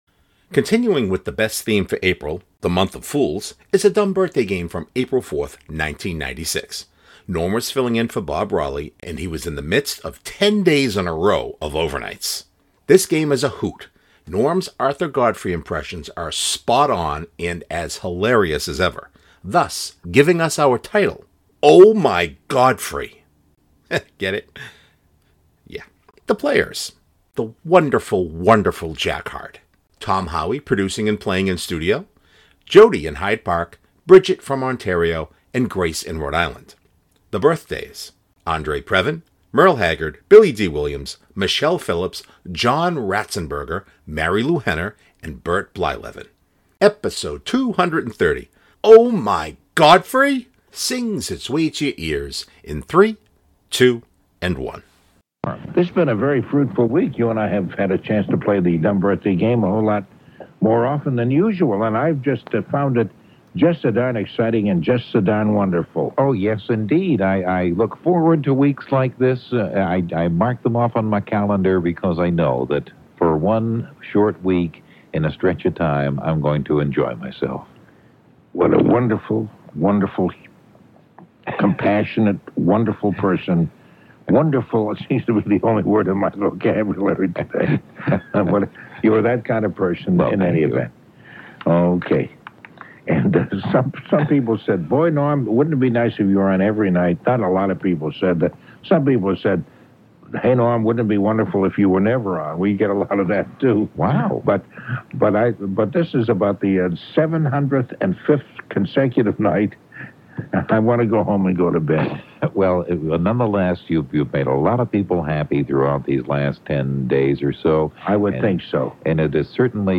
producing and playing in studio